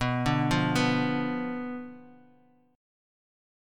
Bdim chord